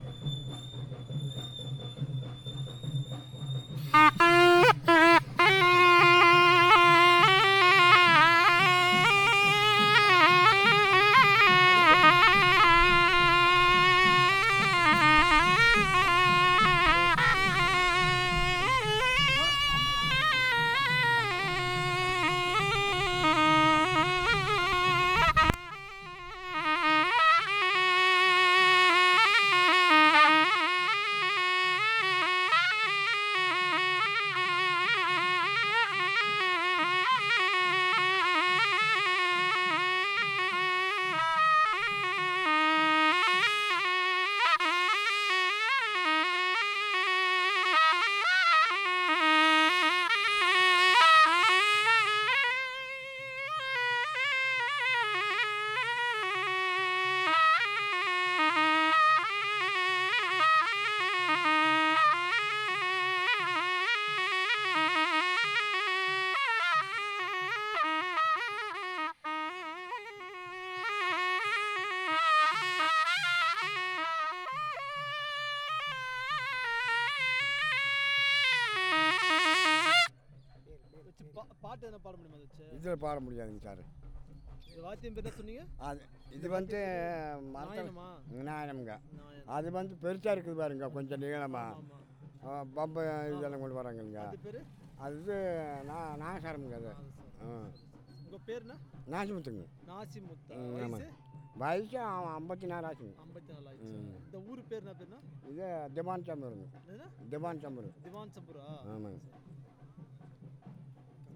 The recording is made at the event of marriage. The Resource Person is recording live performance. This file is a musical performance of a tune in nakuzhal.